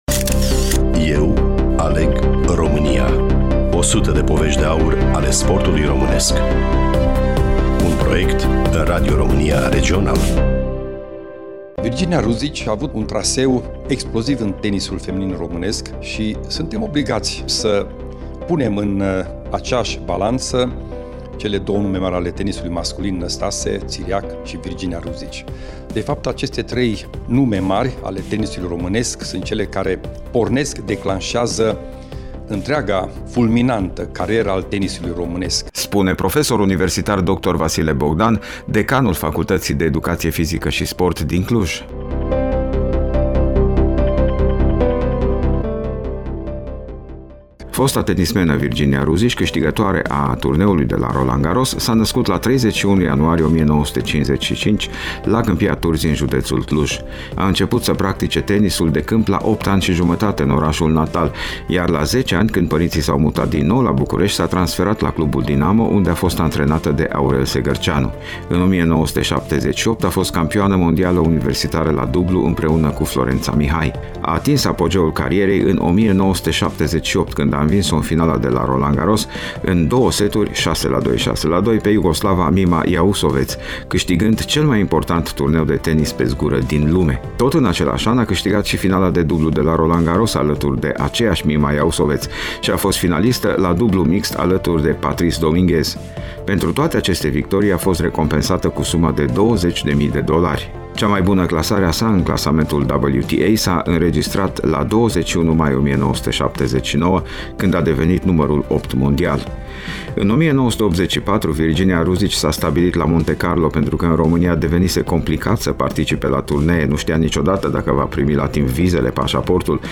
Studioul Regional Radio România Cluj